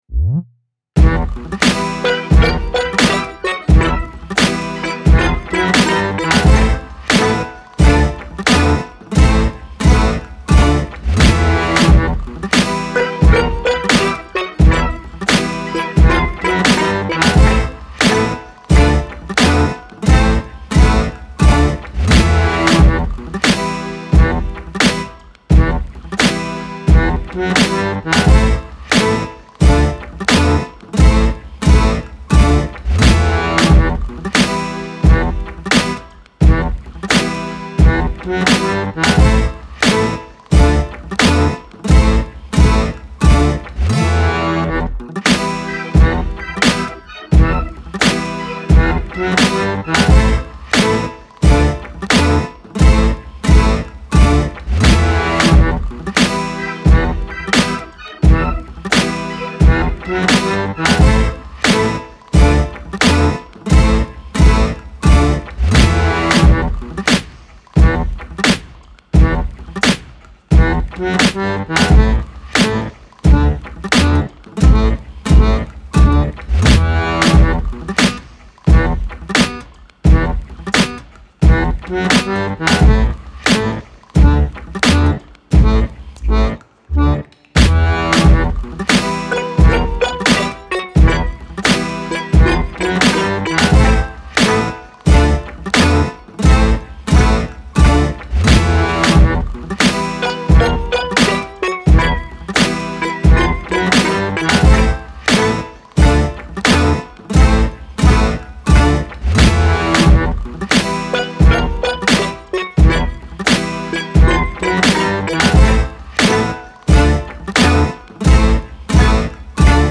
Obscure beat with drunken twisted keys and accordion.